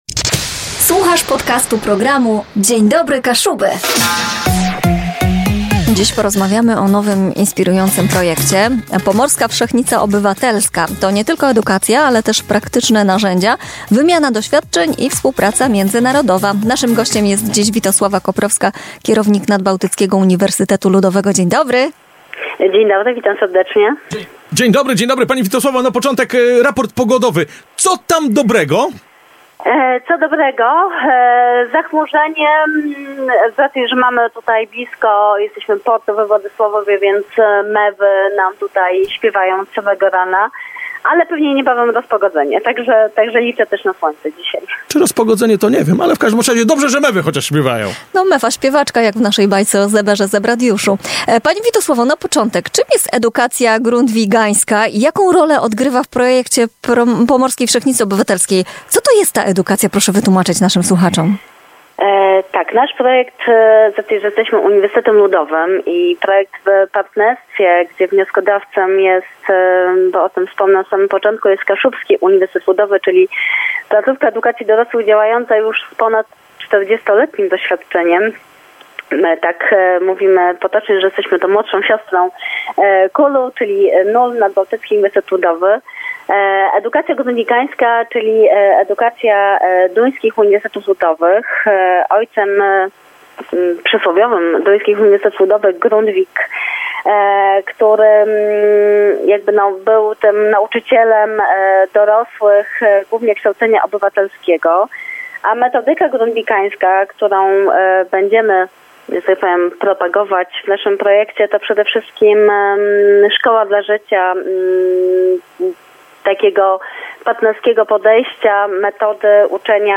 W rozmowie z Radiem Kaszëbë